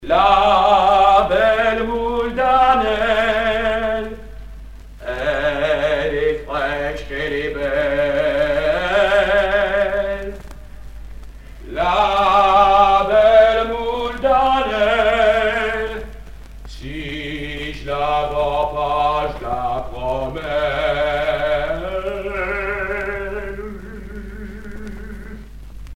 Cris de rue d'un marchand de moules